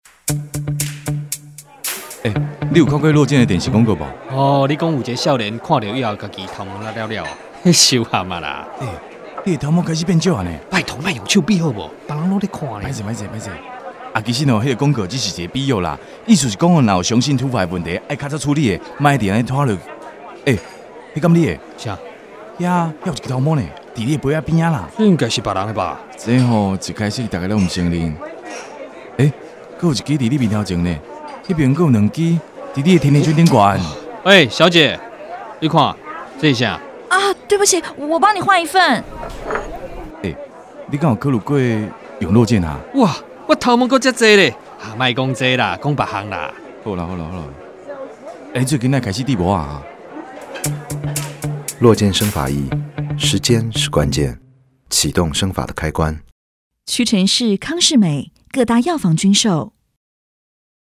國語配音 男性配音員
落建生髮液甜甜圈篇60秒台語版D-OK